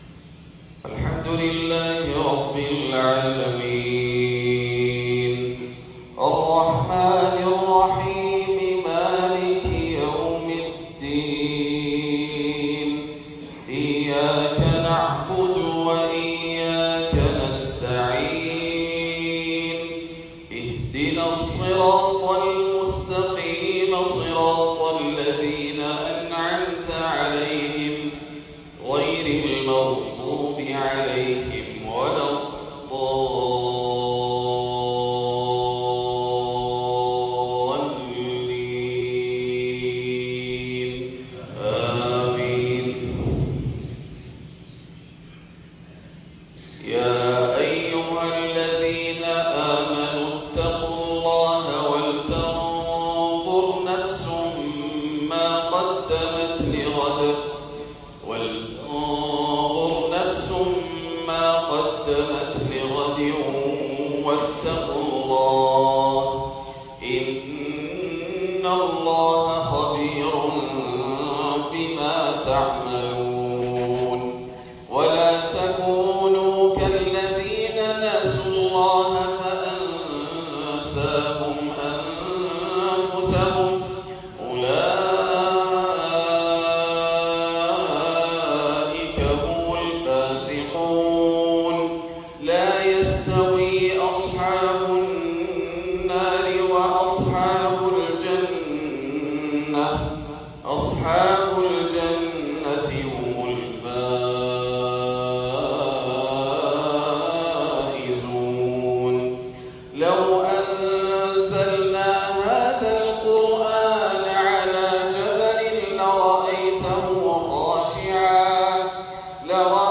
صلاة المغرب للشيخ ياسر الدوسري من جمهورية جنوب أفريقيا 11 ربيع الأول 1446هـ من سورتي الحشر والشمس > زيارة الشيخ ياسر الدوسري لدولة جنوب أفريقيا > المزيد - تلاوات ياسر الدوسري